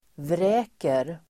Uttal: [vr'ä:ker]